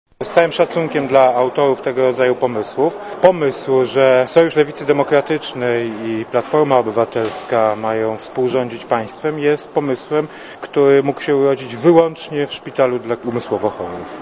* Mówi Jan Rokita*